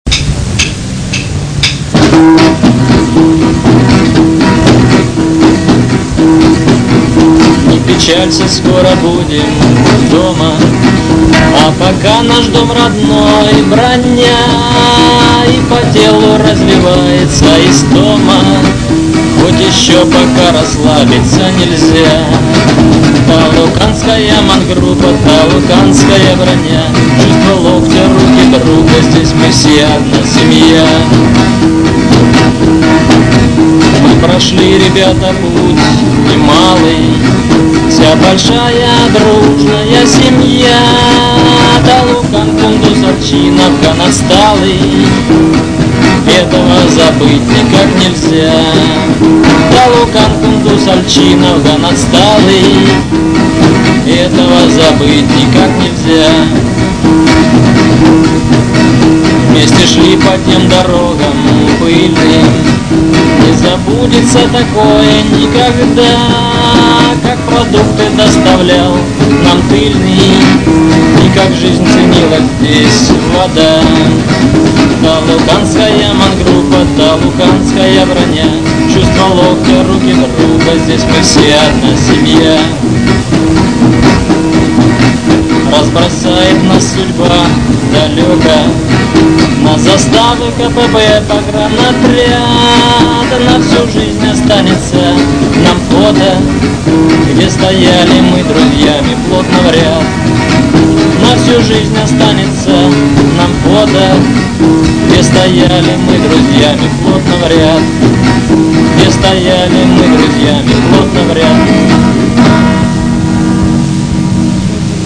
Записано в Афганистане, Тахорская провинция, г.Талукан 1988год.